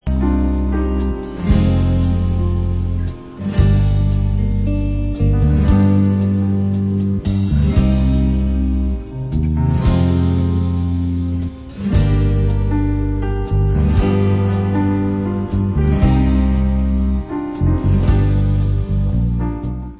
NEW 2005 STUDIO ALBUM